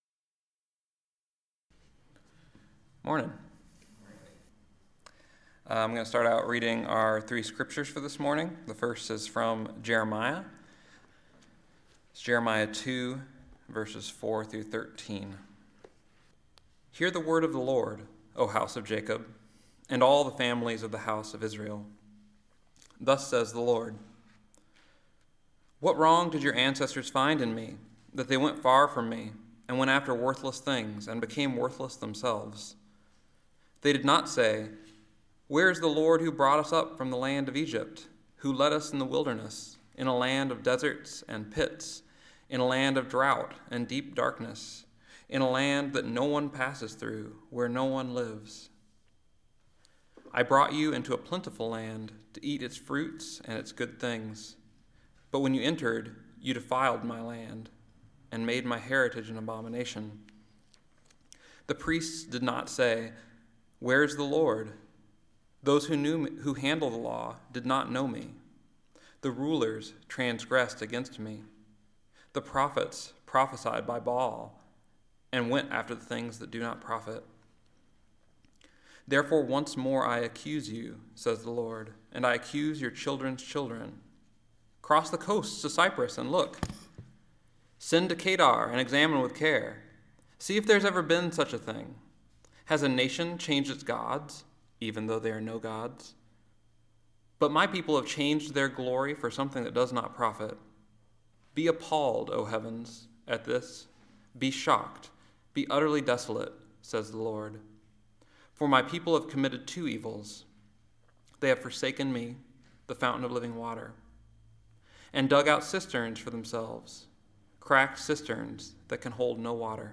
Listen to the most recent message from Sunday worship at Berkeley Friends Church, “Come to the Fountain.”